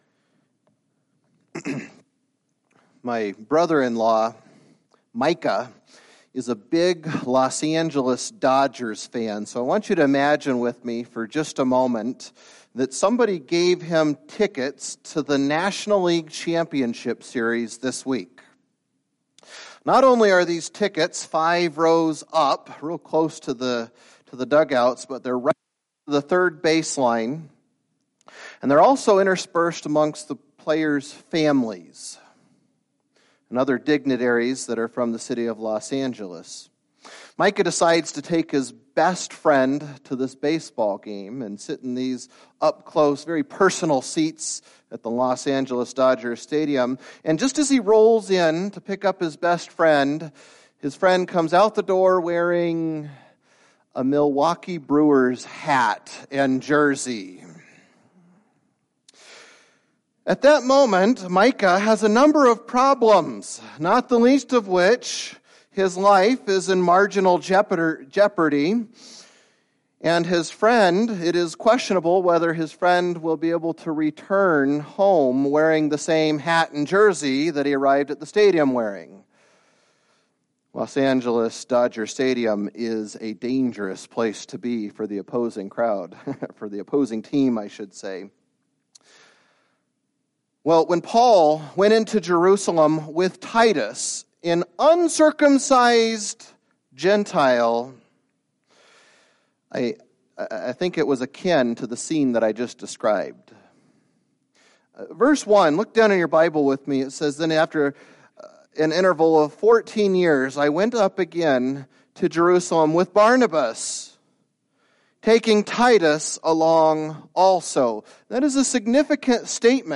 Past Sermons - Kuna Baptist Church